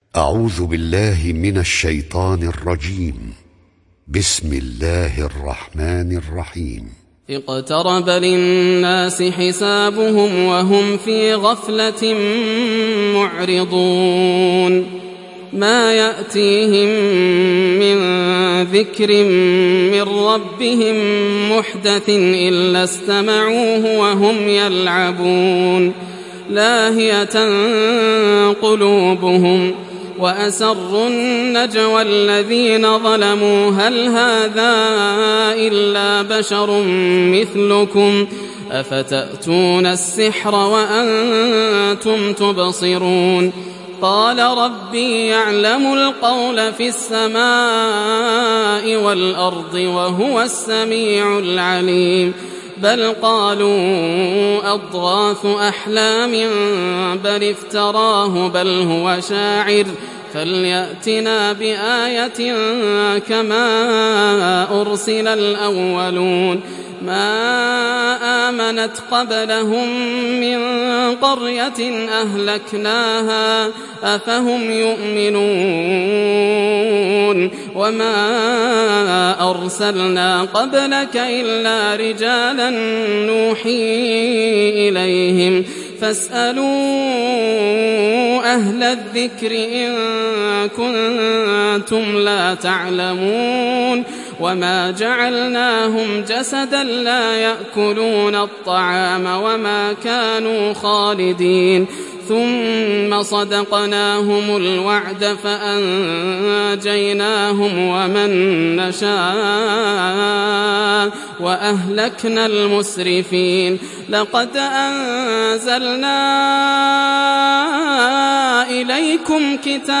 دانلود سوره الأنبياء mp3 ياسر الدوسري روایت حفص از عاصم, قرآن را دانلود کنید و گوش کن mp3 ، لینک مستقیم کامل